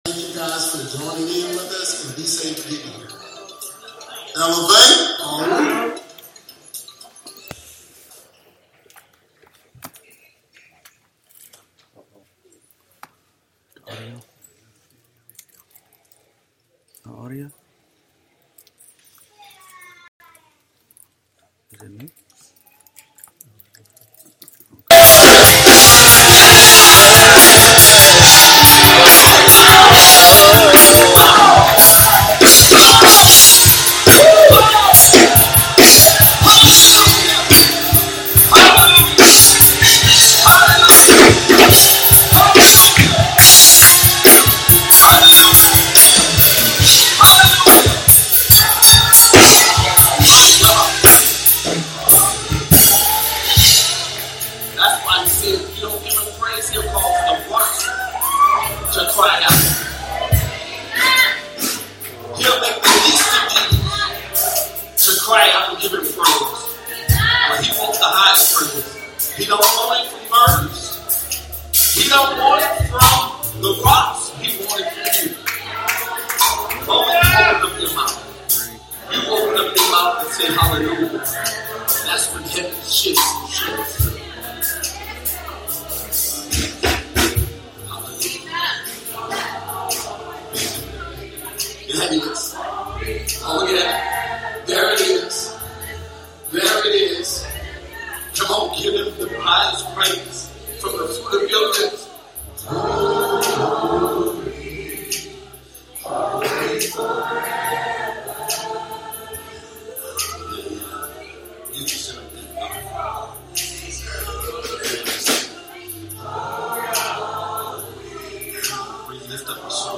Sermons by Elevate Church of Baton Rouge